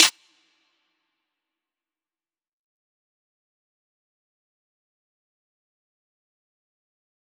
DMV3_Snare 14.wav